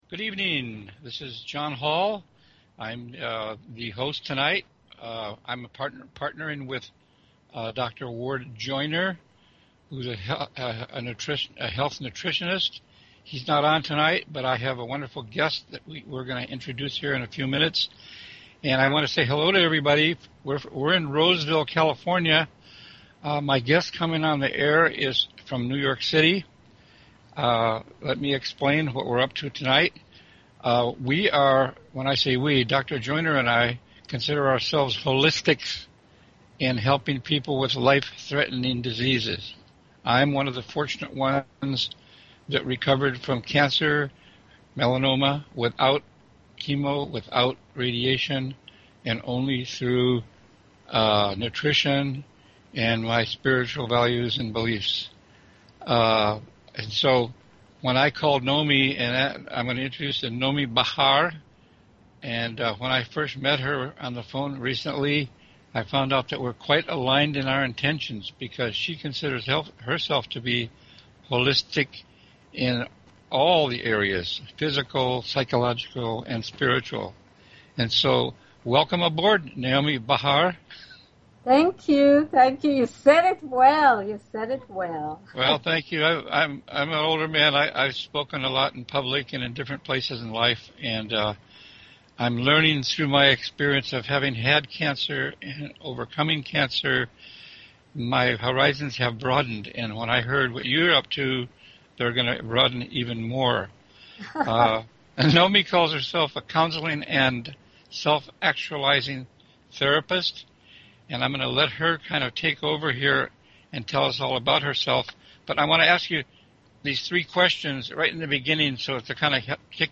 Talk Show Episode, Audio Podcast, Overcoming_Life_Threatening_Diseases and Courtesy of BBS Radio on , show guests , about , categorized as